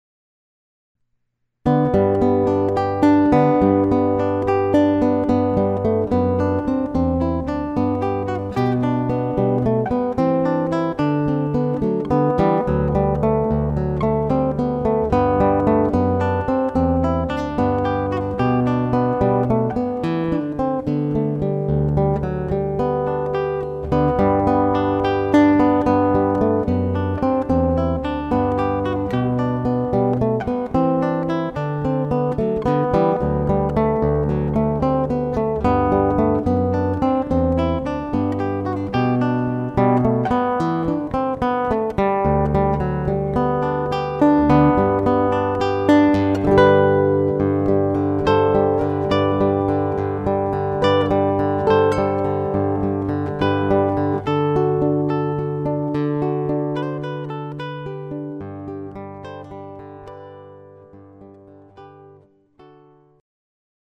The all acoustic album
The guitar has been re-tuned here.